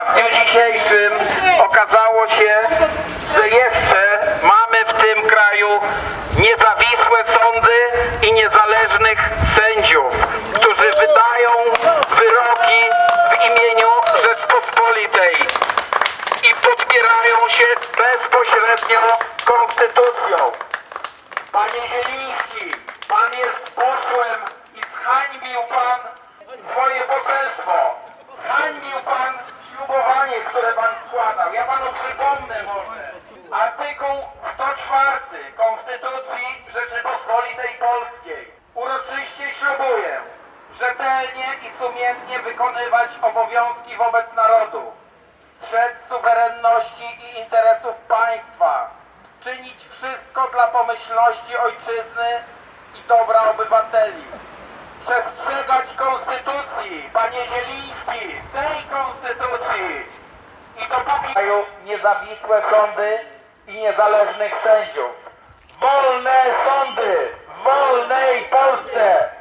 Głośno zarzucali wiceministrowi, że „zhańbił swoje poselstwo”.